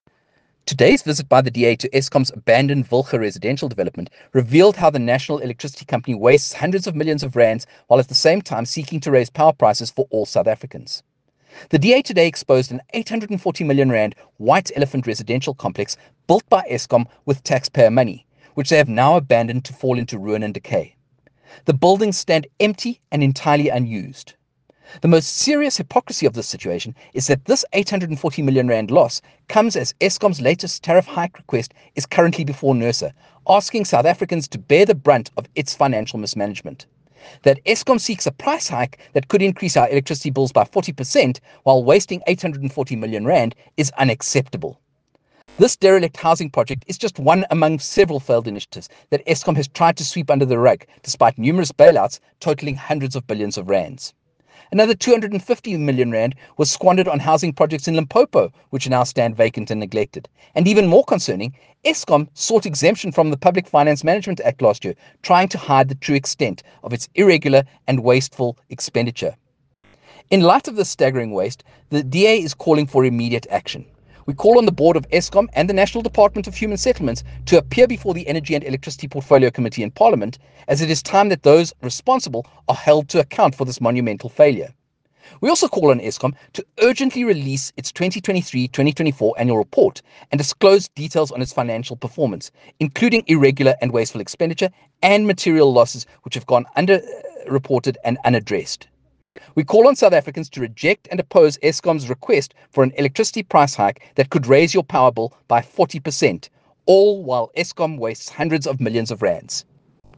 soundbite by Kevin Mileham MP.